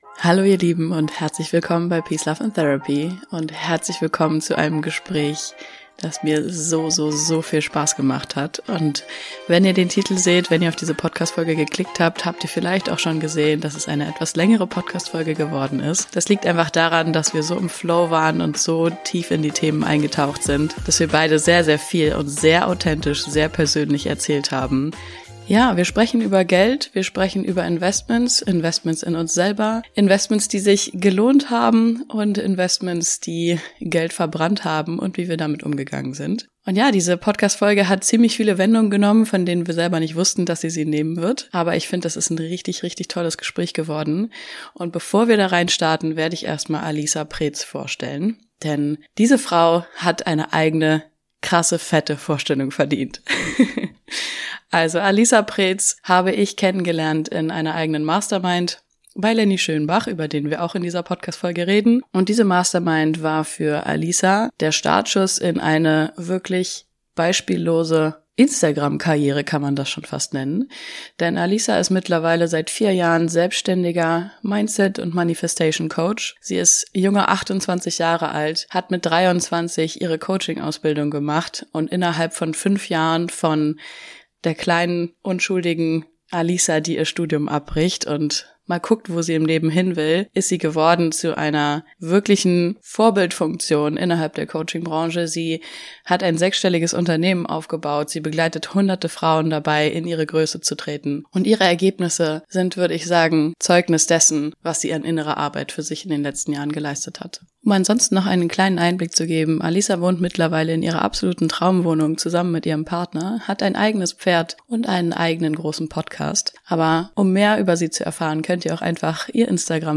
Dieses Gespräch ist ein ehrliches und authentisches Gespräch über finanzielle Investitionen in die eigene Entwicklung, Businessaufbau und den Umgang mit Fehlentscheidungen.